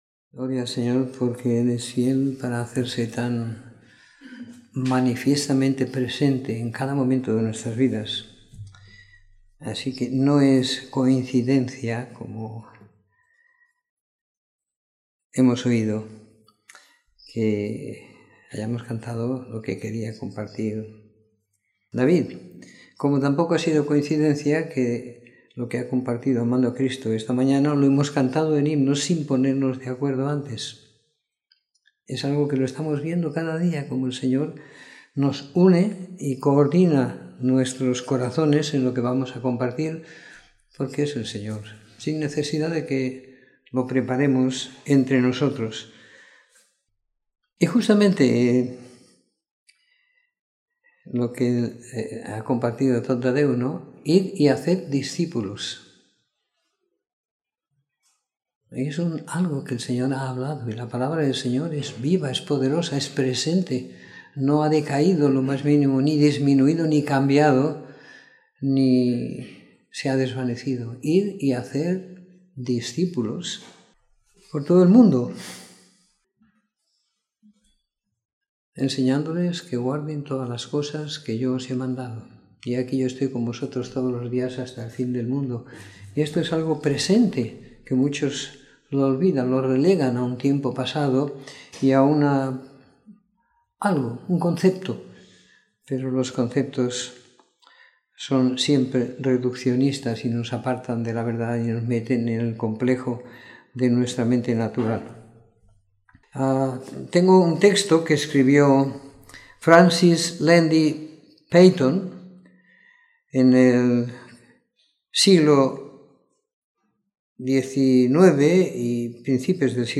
Domingo por la Tarde . 04 de Marzo de 2018